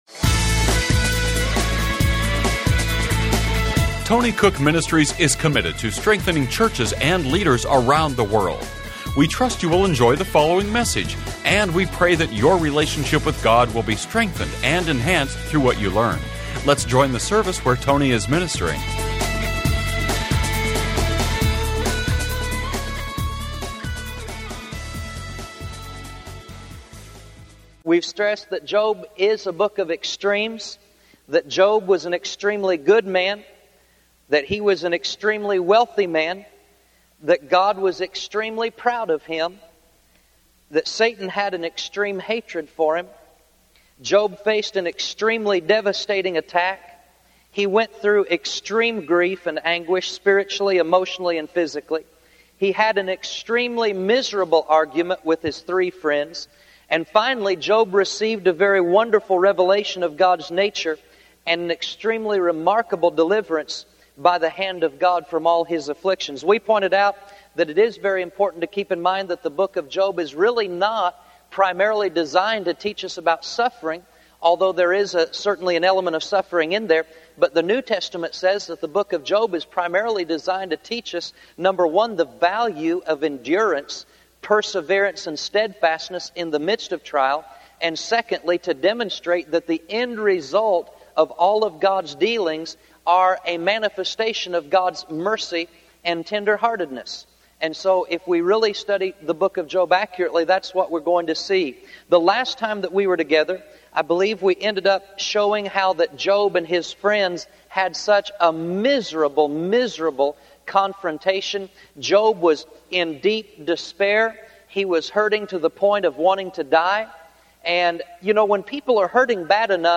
Genre: Christian Teaching.